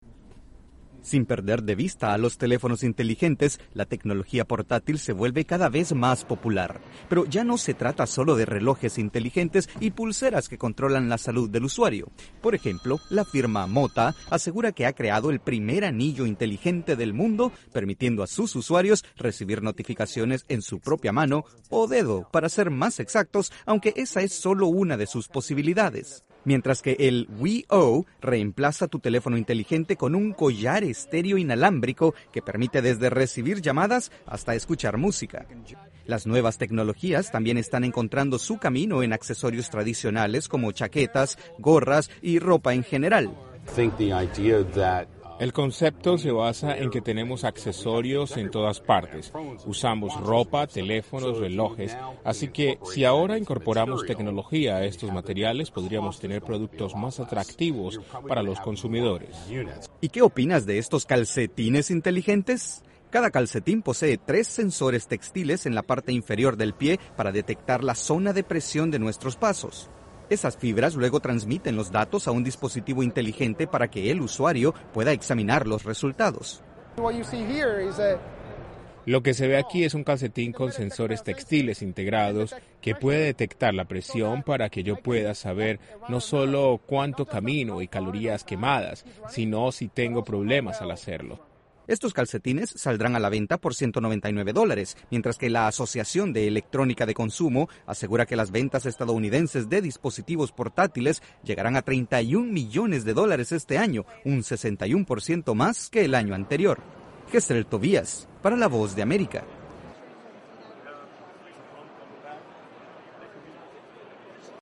Los accesorios portátiles siguen evolucionando para encontrar utilidades nunca antes pensados. Desde los estudios de la Voz de América en Washington informa